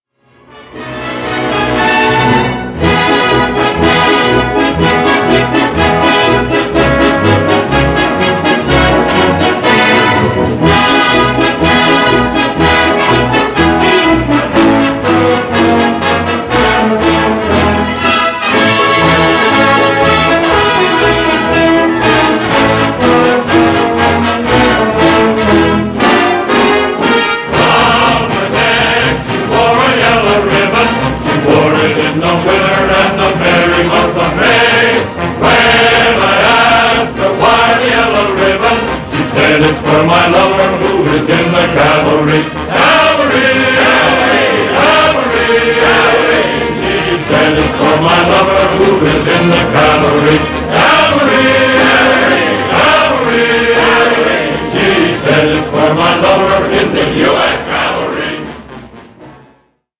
Musica:
Original Track Music